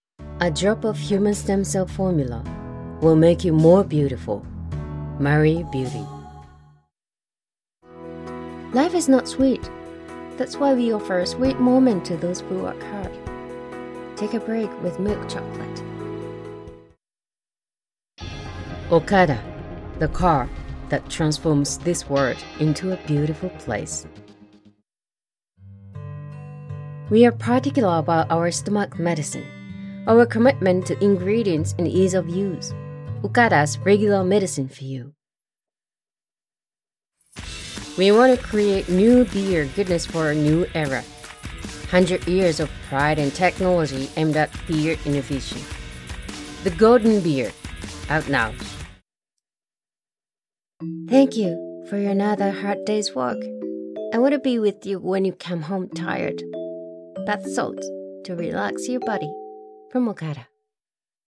Kommerzielle Demo
Ihre strahlende, tiefe Stimme hat eine ausgeprägte Überzeugungskraft und einen vertrauenerweckenden Klang.